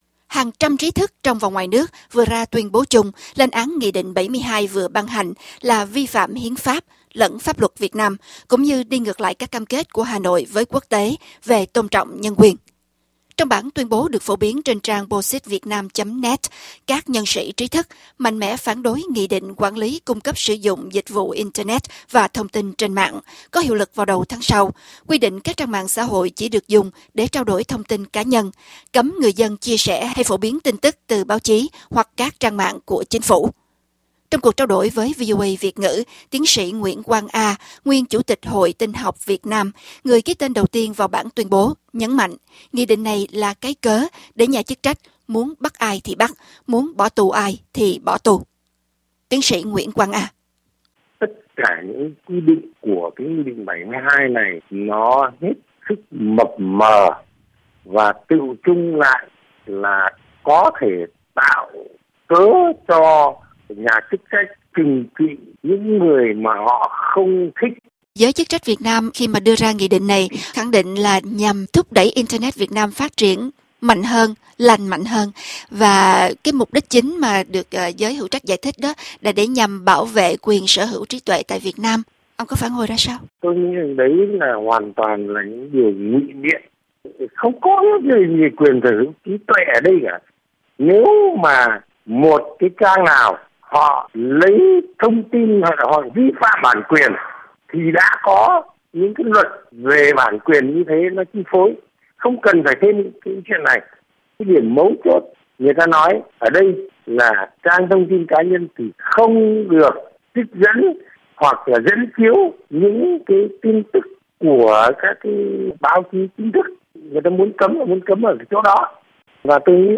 Trong cuộc trao đổi với VOA Việt ngữ, Tiến sĩ Nguyễn Quang A, nguyên Chủ tịch Hội Tin học Việt Nam, người ký tên đầu tiên vào bản Tuyên bố, nhấn mạnh Nghị định này là cái cớ để nhà chức trách “muốn bắt ai thì bắt, muốn bỏ tù ai thì bỏ tù”.